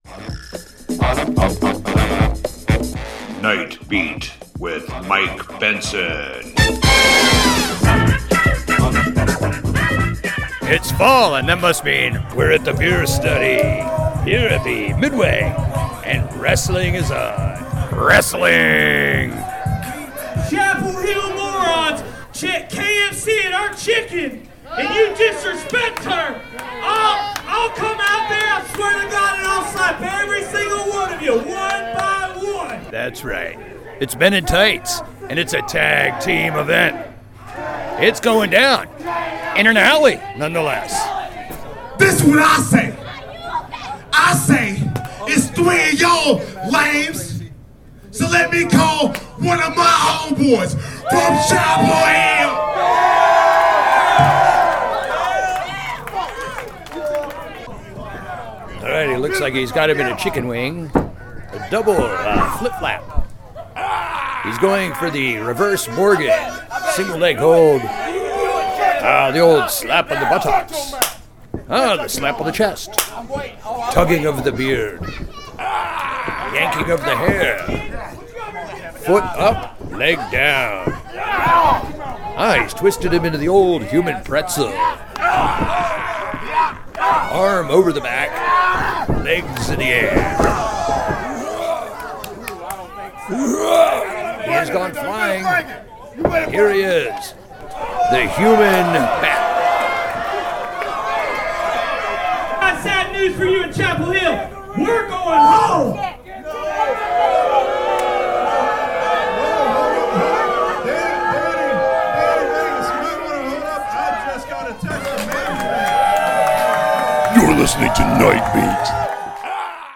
wrestling.mp3